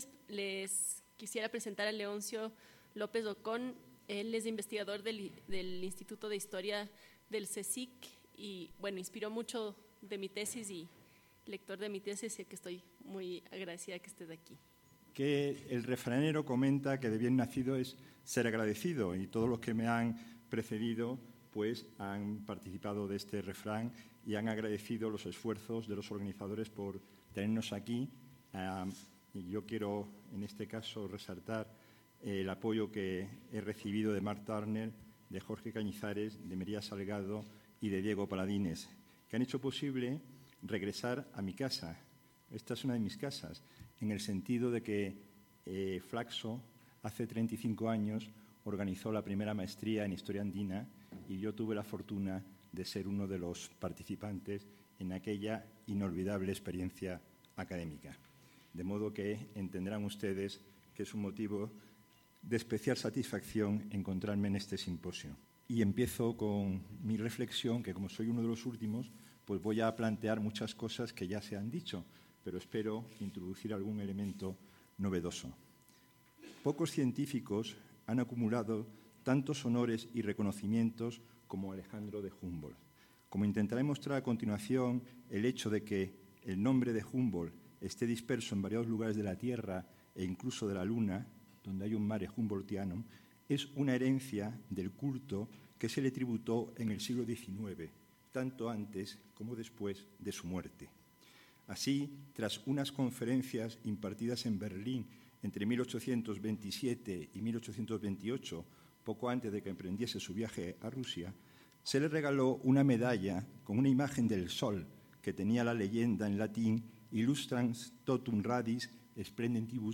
Esta exposición intenta desvelar las razones por las que el naturalista berlinés recibió de sus coetáneos honores propios de los dioses.